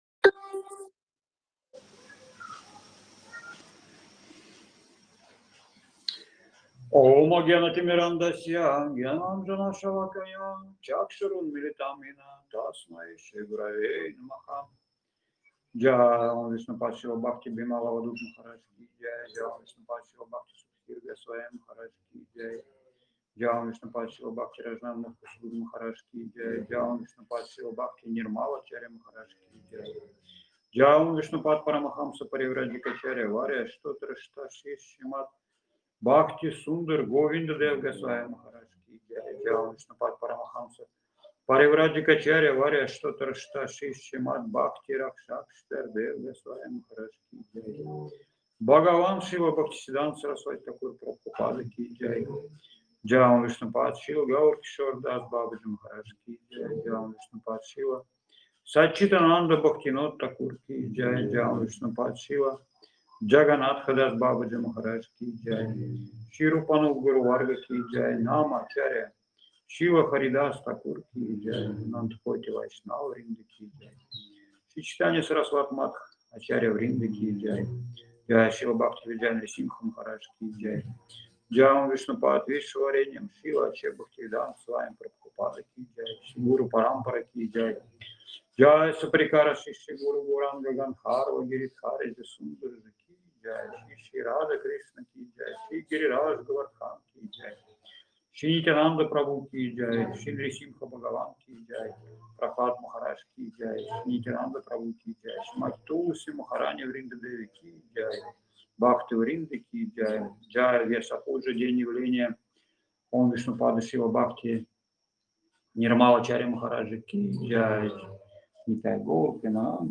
Лекции полностью
Киртан
Воспевание Святого Имени